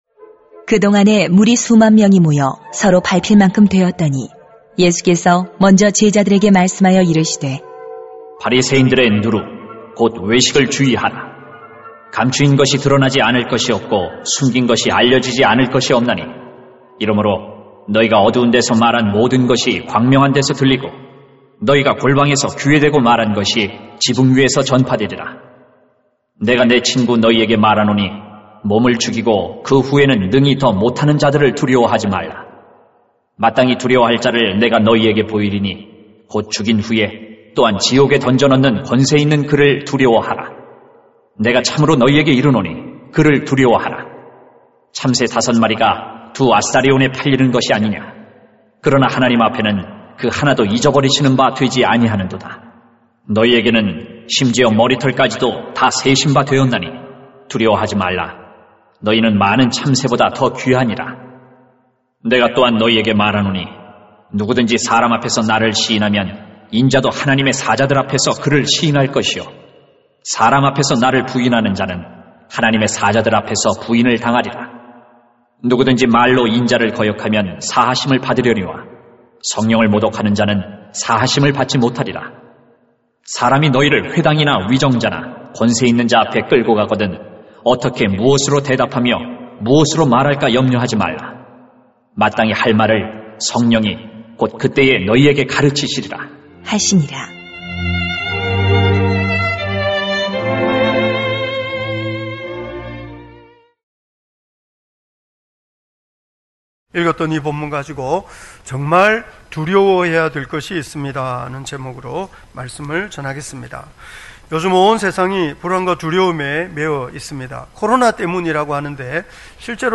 2021.2.7 정말 두려워해야 될 것이 있습니다 > 주일 예배 | 전주제자교회